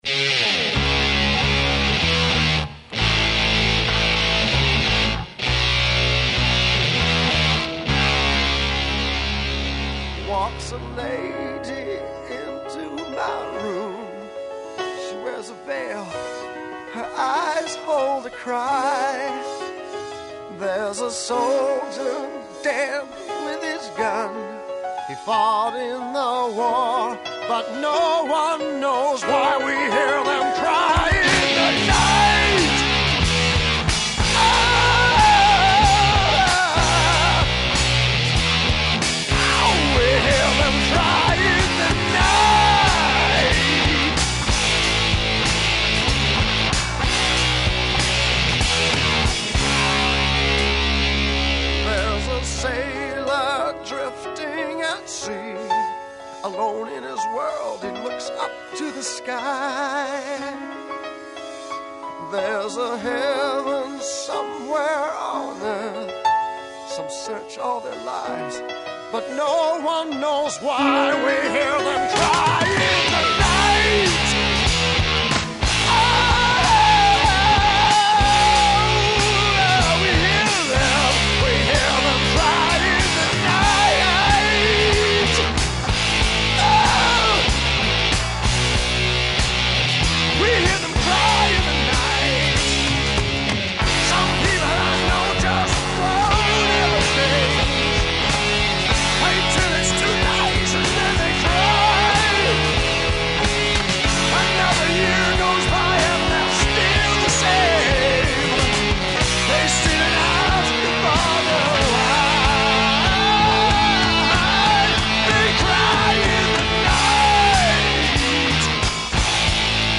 I had just finished singing this (I should say screaming this) late one evening in Randy's studio when